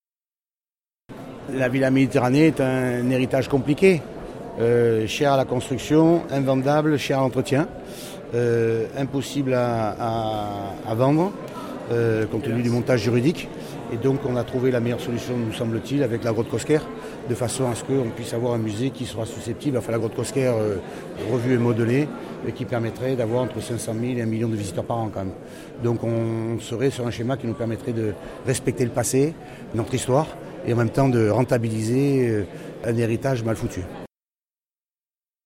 Les journalistes étaient présents en nombre pour les vœux à la presse de Renaud Muselier.